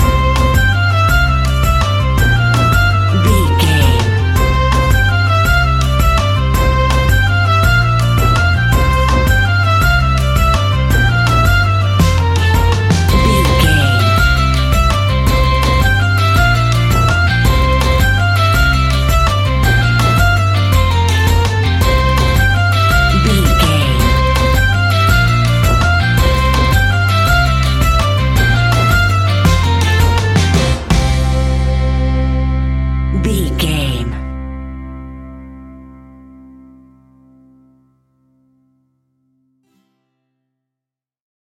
Mixolydian
sea shanties
earthy
acoustic guitar
mandolin
ukulele
lapsteel
drums
double bass
accordion